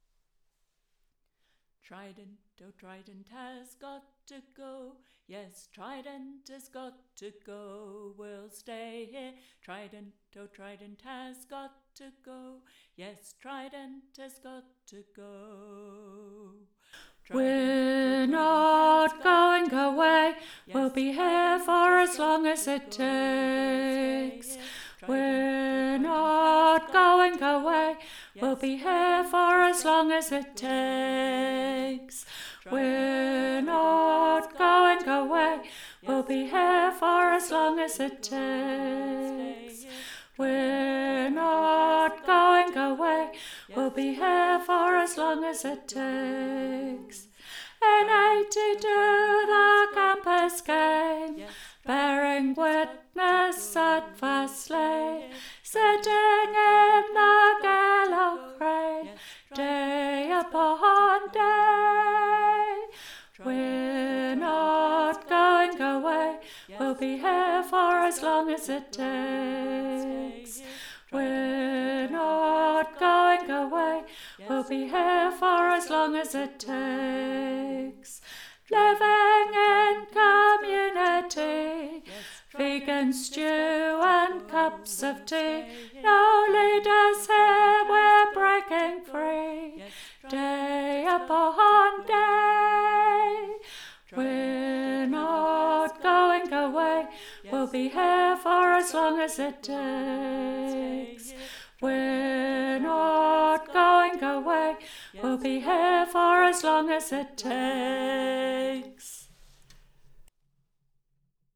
Tune: